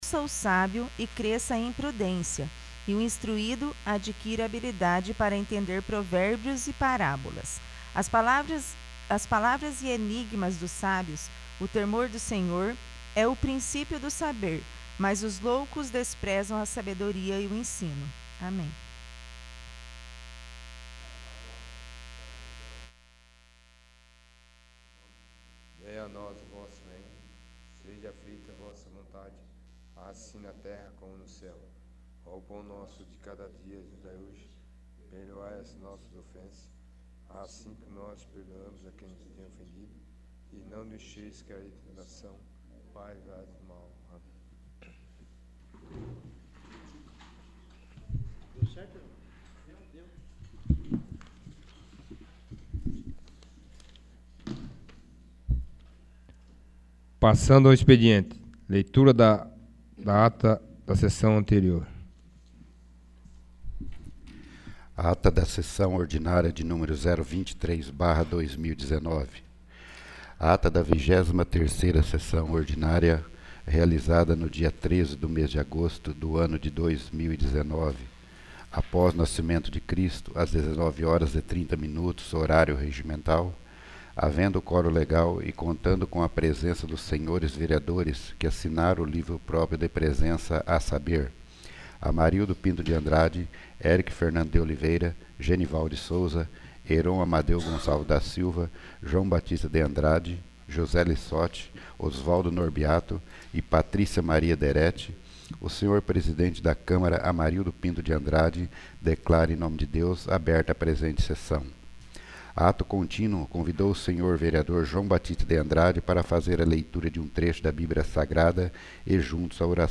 24º. Sessão Ordinária 20/08/2019
24º. Sessão Ordinária